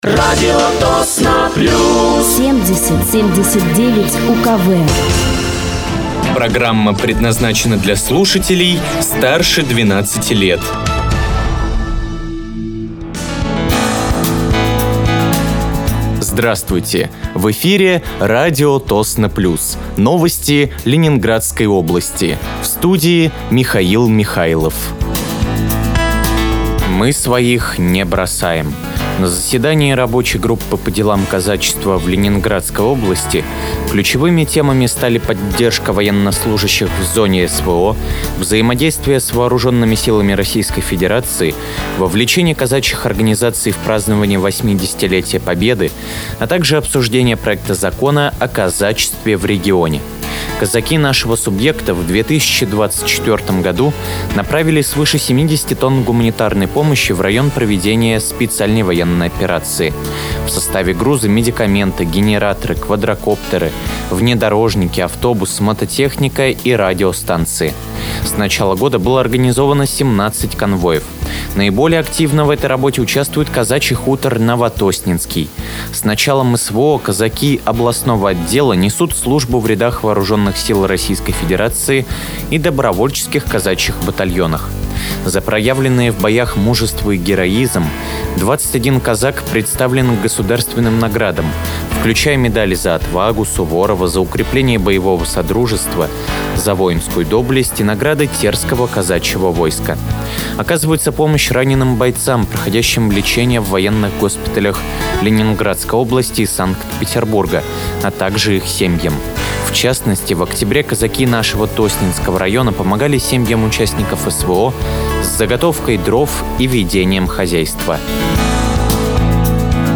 Вы слушаете новости Ленинградской области на радиоканале «Радио Тосно плюс».